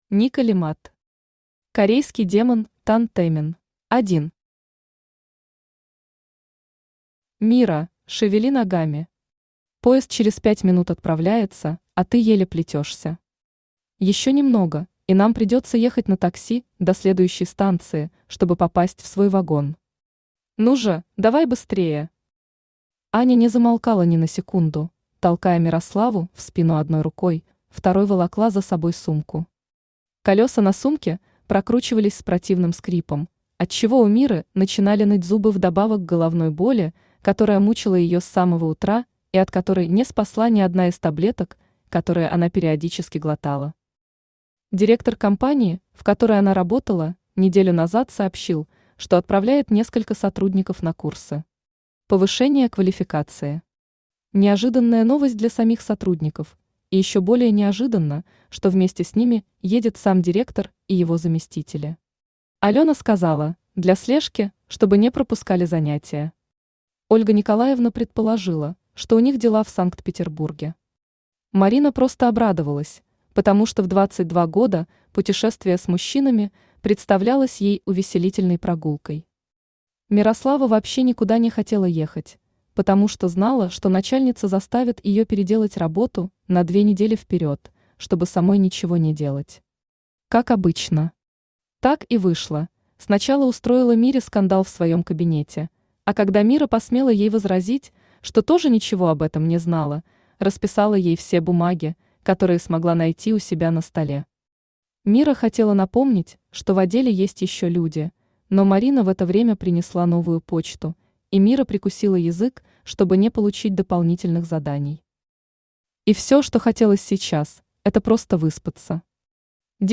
Аудиокнига Корейский демон Тан Тэмин | Библиотека аудиокниг
Aудиокнига Корейский демон Тан Тэмин Автор Ника Лемад Читает аудиокнигу Авточтец ЛитРес.